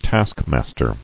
[task·mas·ter]